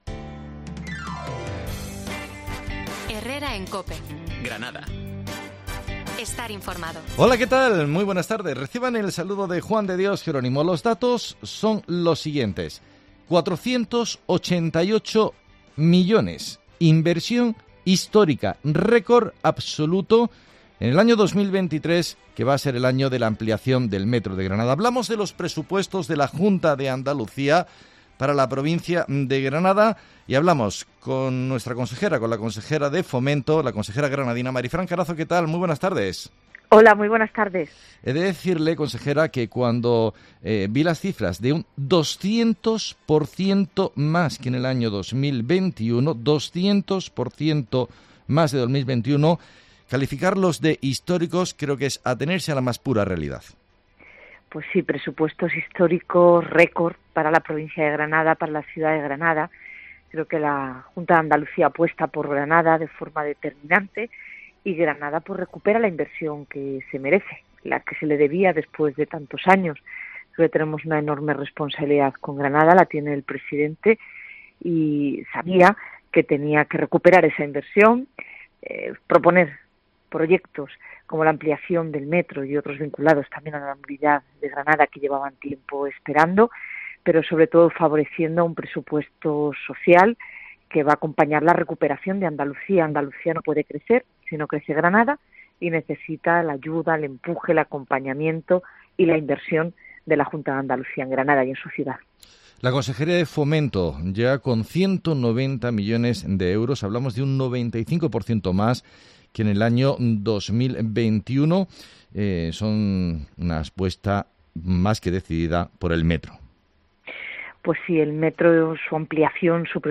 Así ha explicado en COPE Marifrán Carazo la inversión "histórica" de 488 millones en Granada en 2023